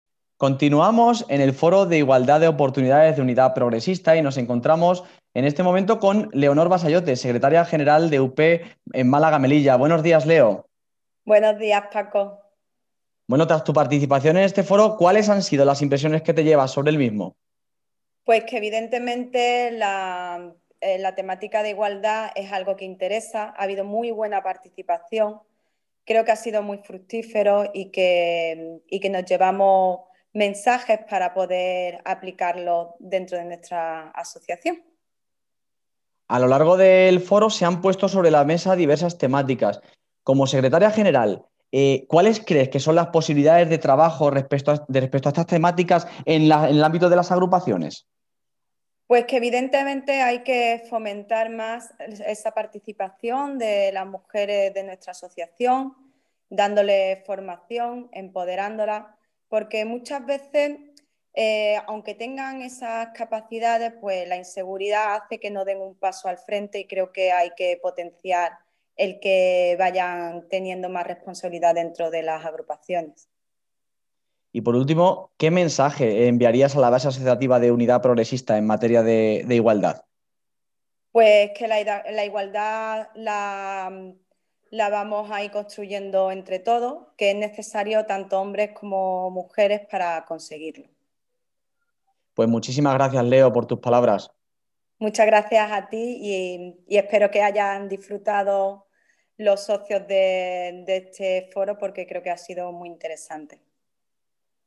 A continuación os compartimos algunos testimonios sonoros recogidos en el Foro: